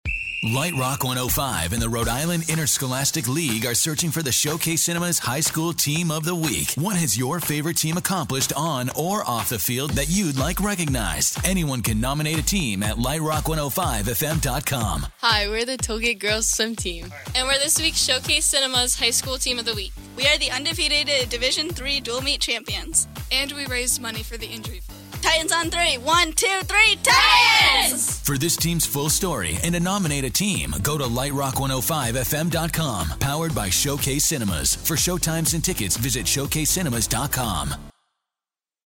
Lite Rock 105 On-Air Spot